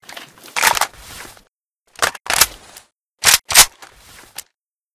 aks74u_reload_empty.wav